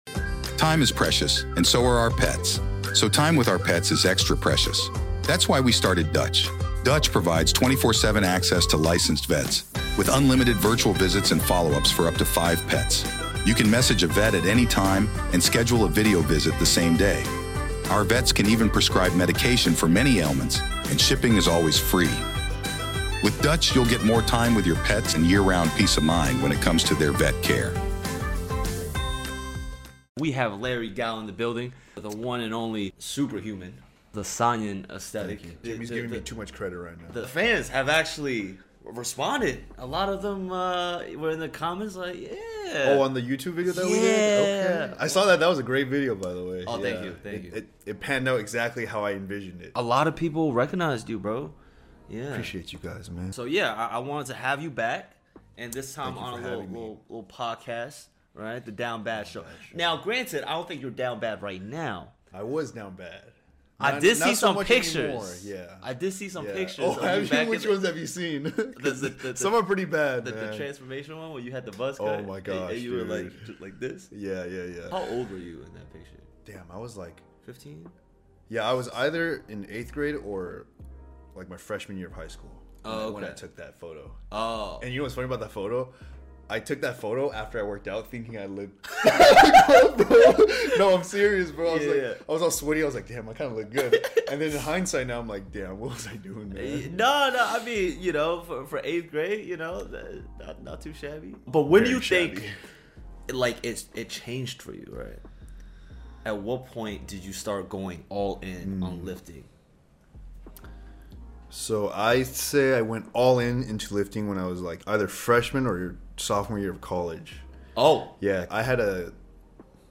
Today I interview